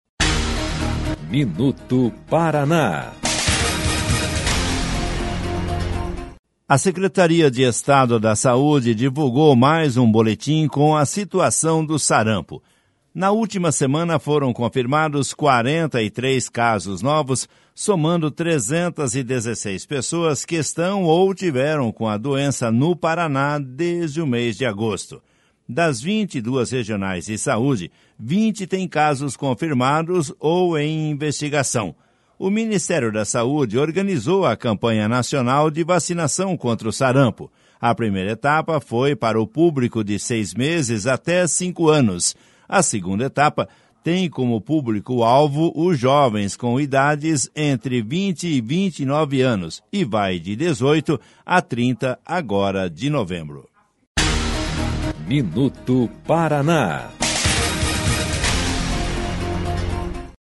MINUTO PARANÁ - BOLETIM SARAMPO - ATUALIZADO